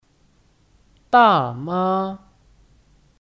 大妈 (Dàmā 大妈)